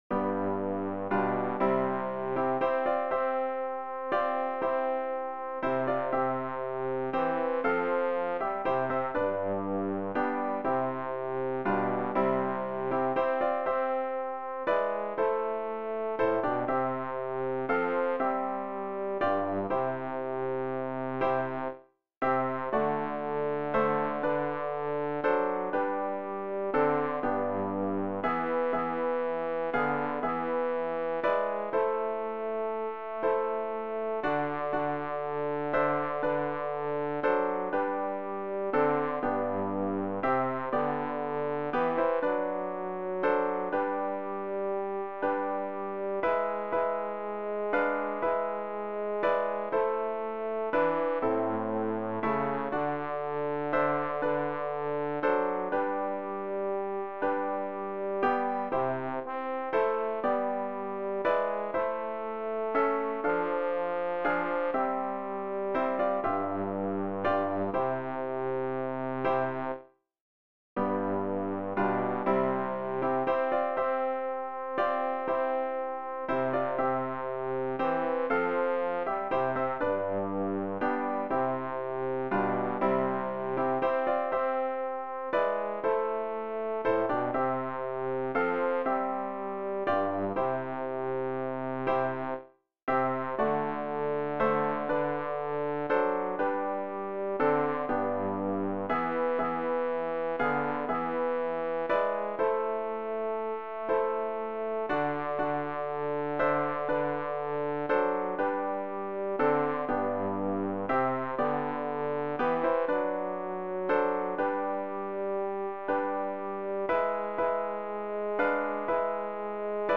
Übehilfen für das Erlernen von Liedern